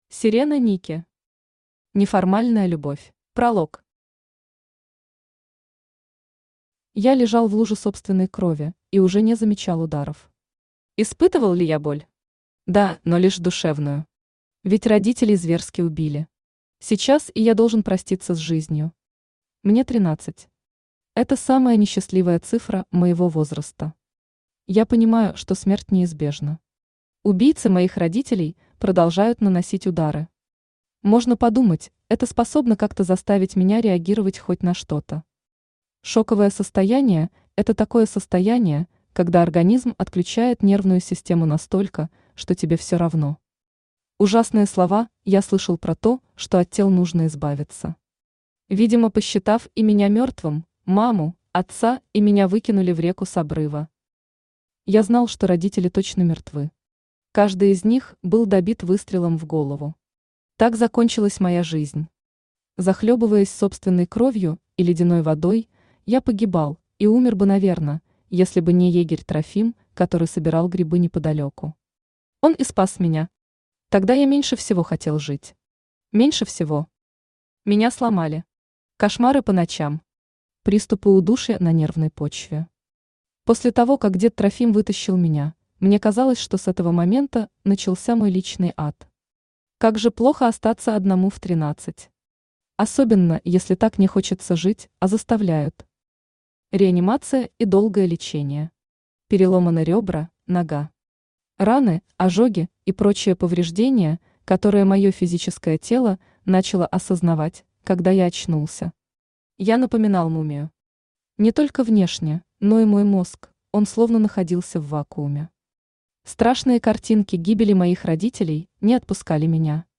Аудиокнига Неформальная любовь | Библиотека аудиокниг